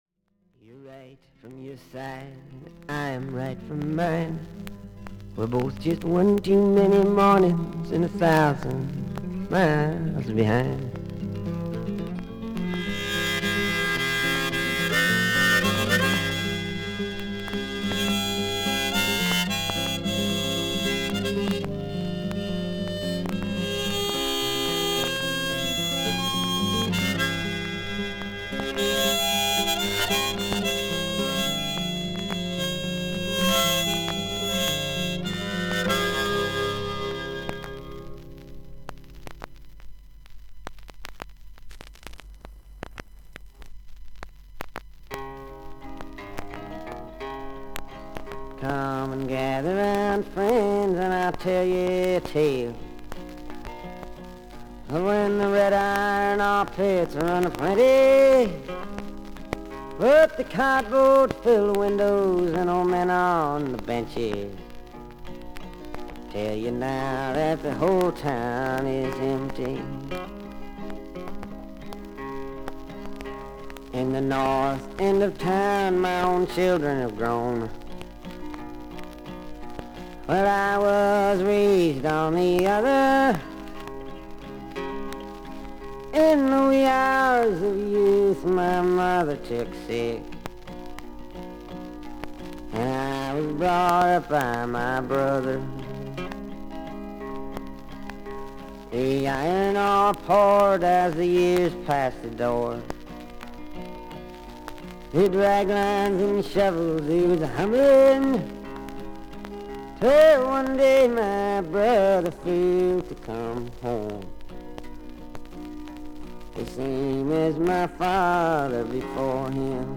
A4終盤からA5終わりまでに複数のキズがあり、全体にノイズあり。
ほかはVG+:所々に少々軽いパチノイズの箇所あり。少々サーフィス・ノイズあり。